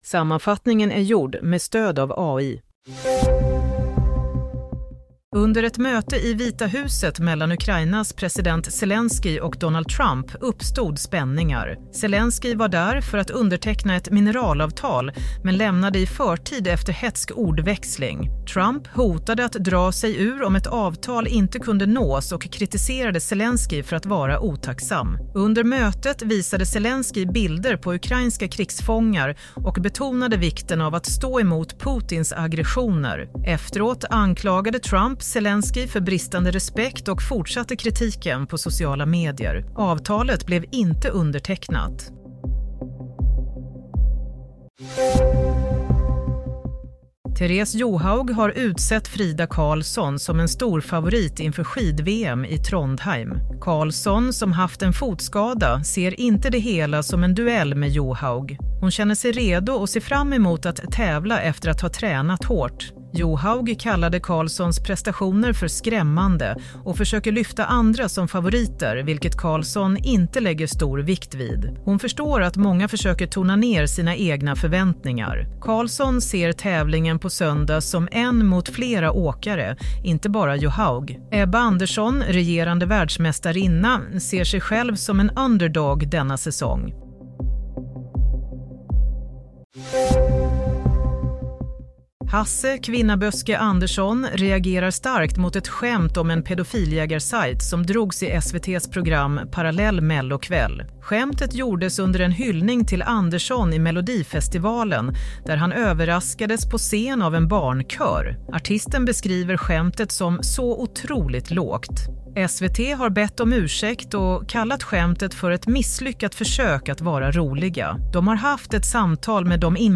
Nyhetssammanfattning - 28 februari 23.00